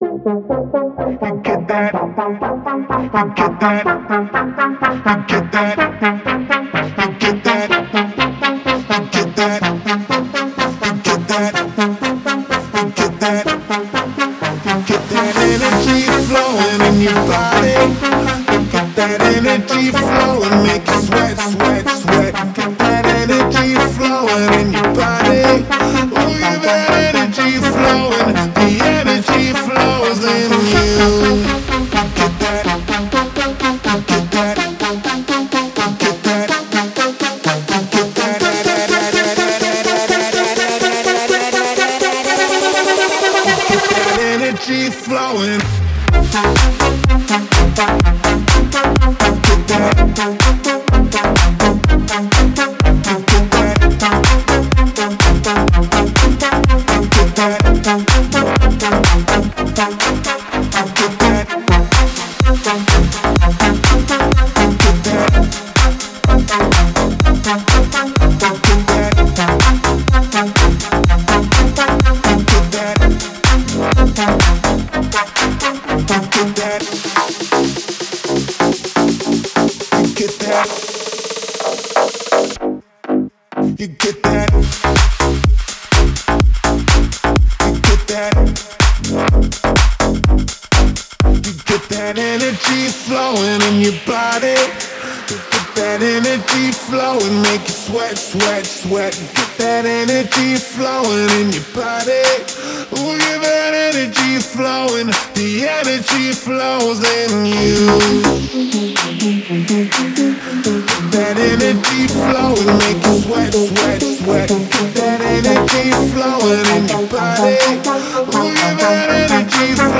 сочетая элементы EDM и поп-музыки